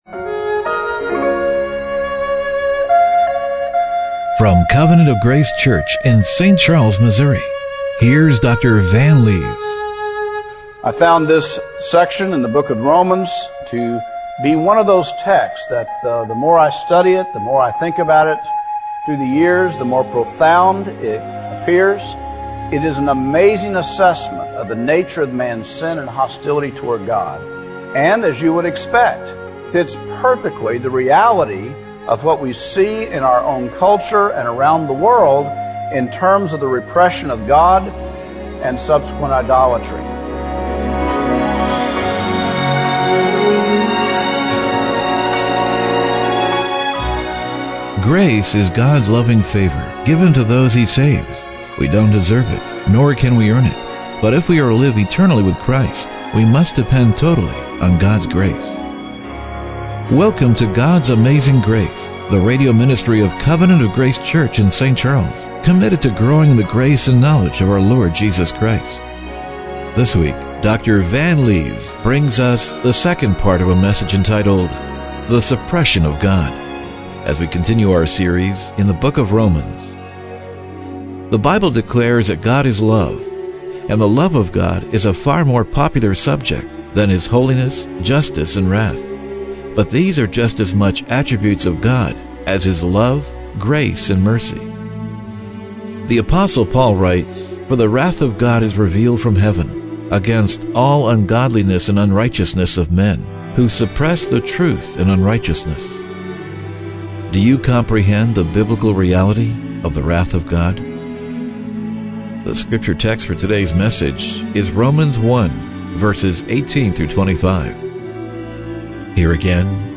Romans 1:18-25 Service Type: Radio Broadcast Do you comprehend the biblical reality of the wrath of God?